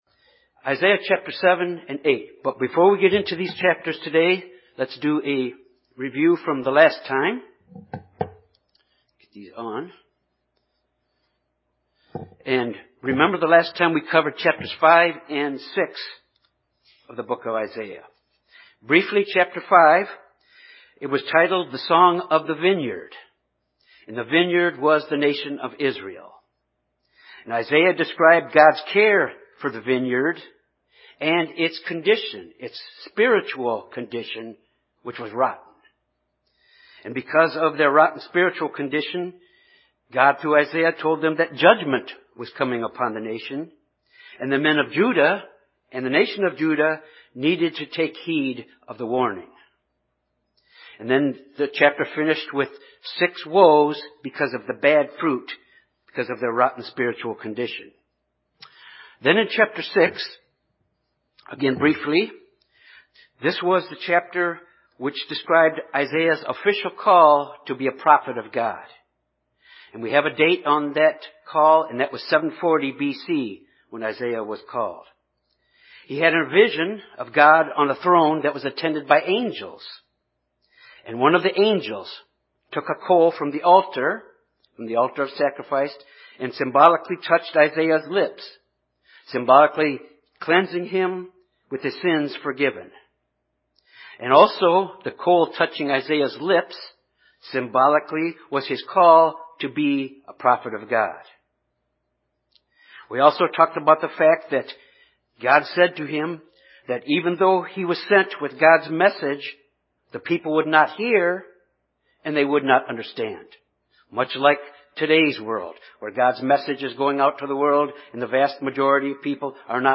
This Bible study covers Isaiah chapters 7 and 8 which shows us to put our trust in God and not in man.